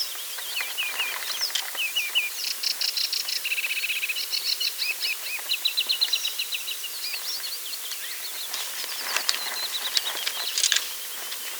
sedge warbler